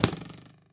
bowhit1.mp3